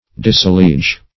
Search Result for " disalliege" : The Collaborative International Dictionary of English v.0.48: Disalliege \Dis`al*liege"\ (d[i^]s`[a^]l*l[=e]j"), v. t. To alienate from allegiance.